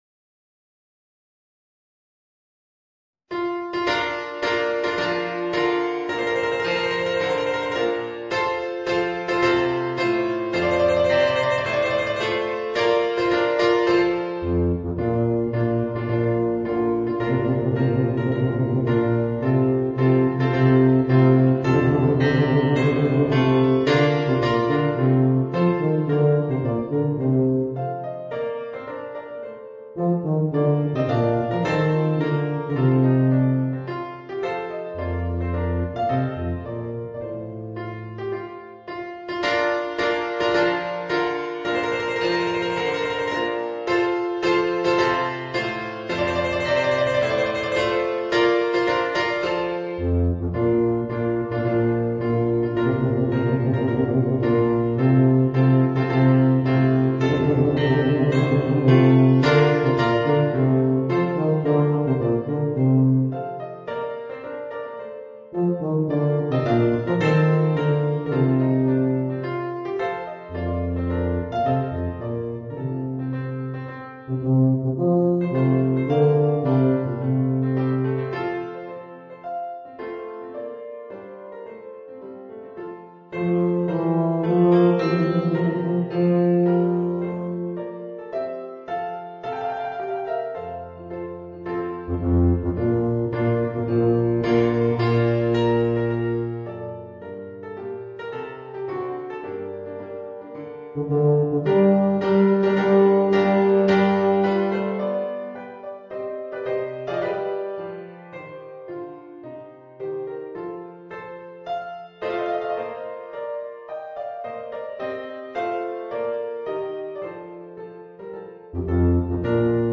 Voicing: Eb Bass and Piano